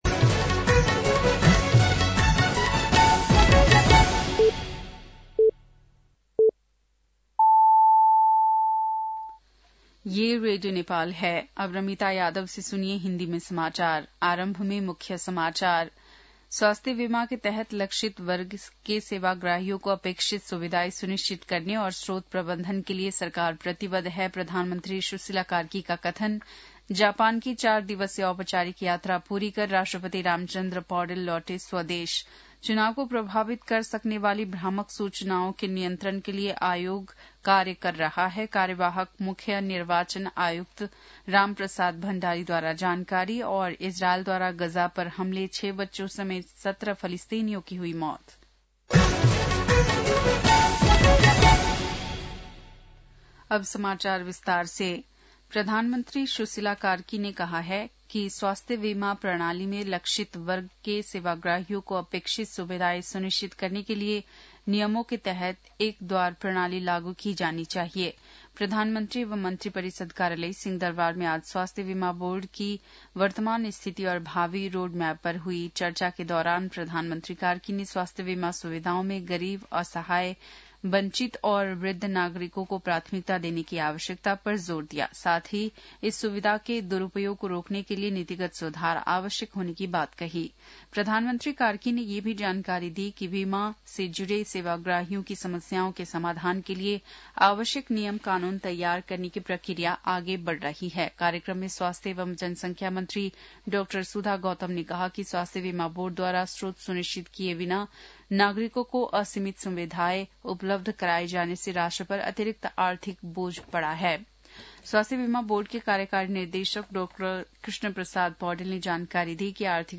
बेलुकी १० बजेको हिन्दी समाचार : २१ माघ , २०८२
10-.-pm-hindi-news-.mp3